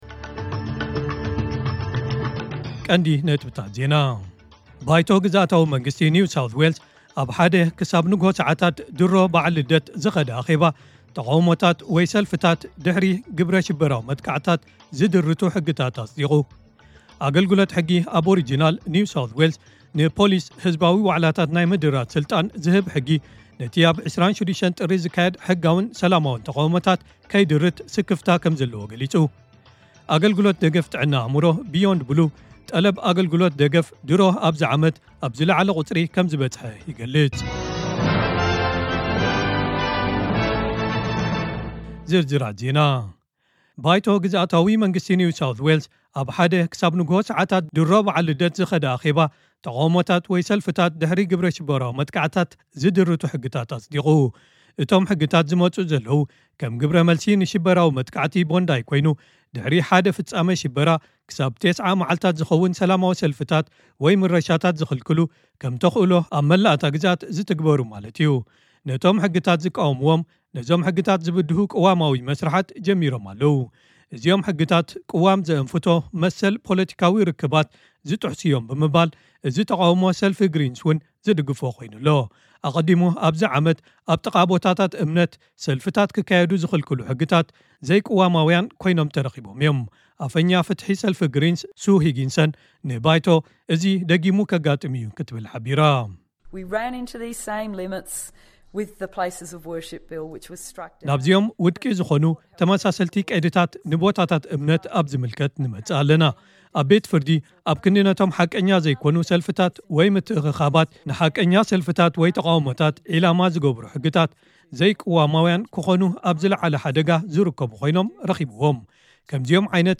ዕለታዊ ዜና ኤስቢኤስ ትግርኛ (25 ታሕሳስ 2025)